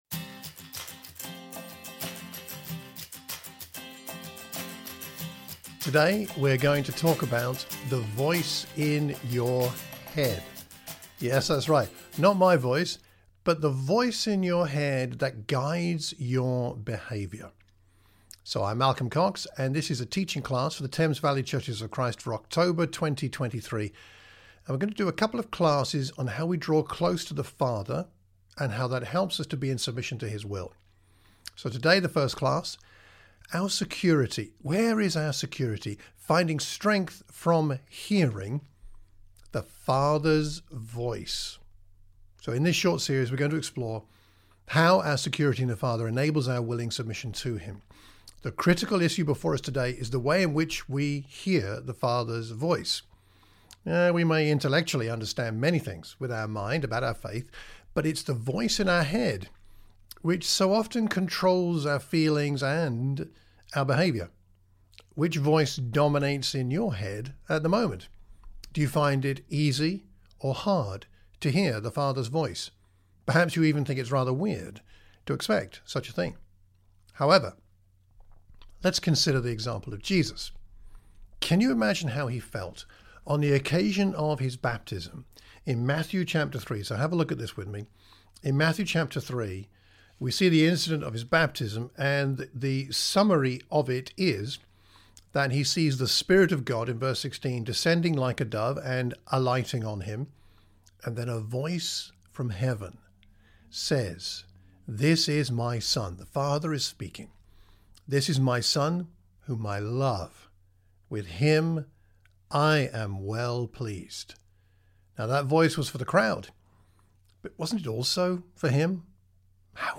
A teaching class for the Thames Valley churches of Christ.